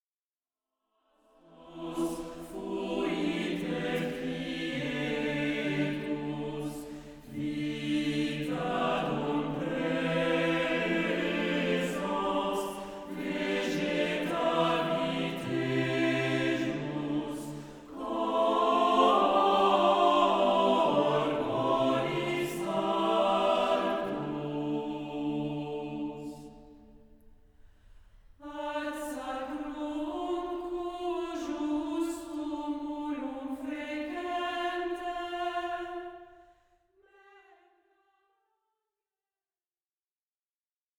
Plain-chant et polyphonies du 14e siècle
Hymne